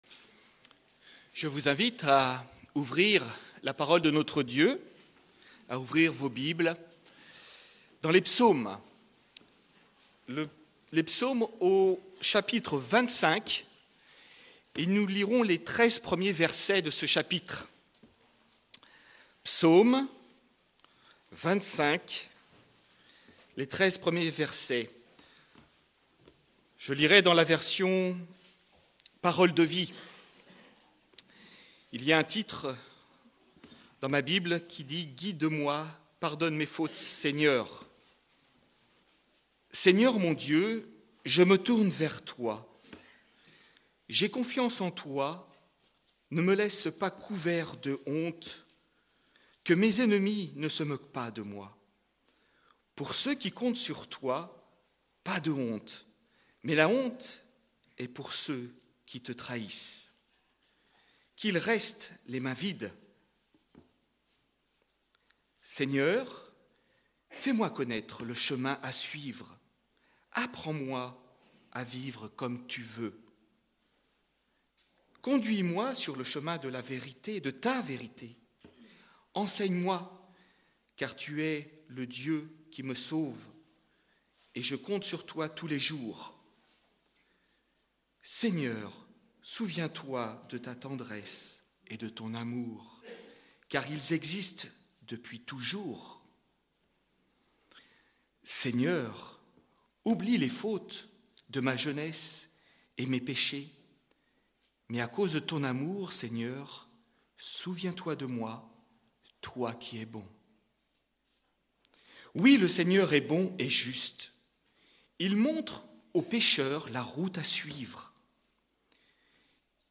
02-Culte_Le_vouloir_de_Dieu_pour_nous.mp3